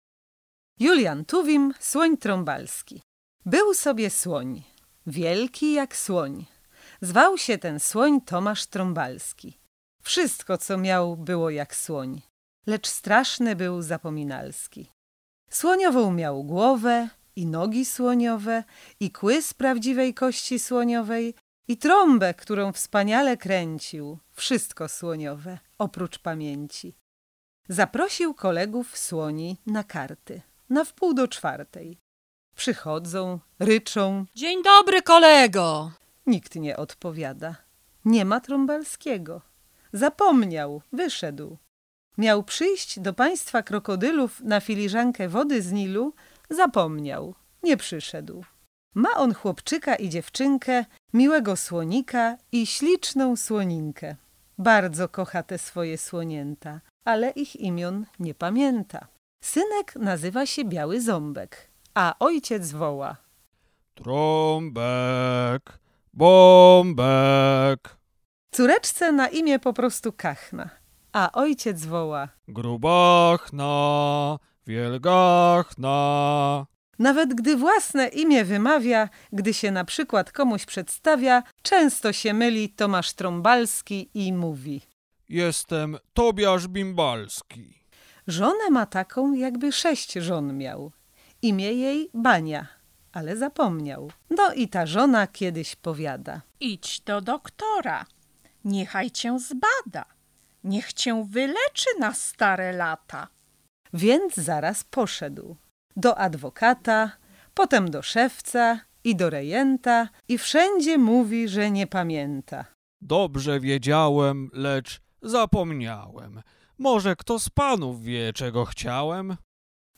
Słuchowisk dla najmłodszych ciąg dalszy
Wiersze czytają: